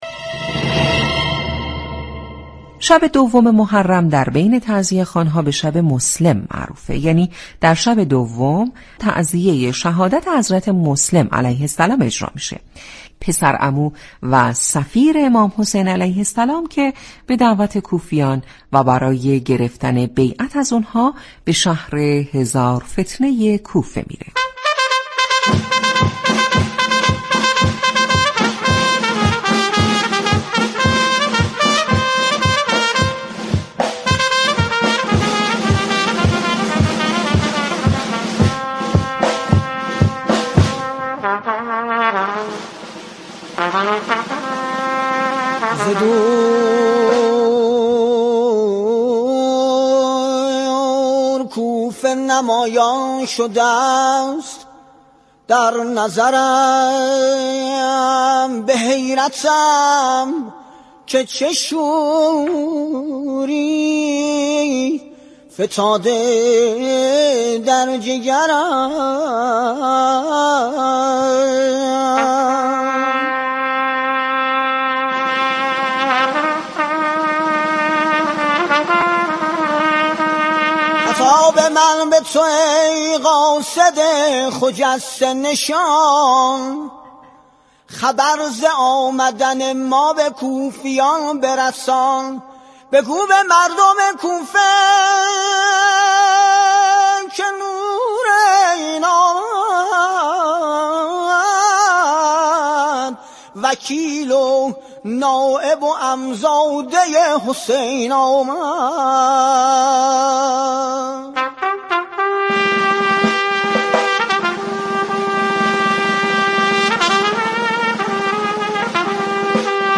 در«مجلس شبیه» گزارشگران به اماكن قدیمی شهرستانها می روند و با تعزیه خوان ها گفتگو می كند .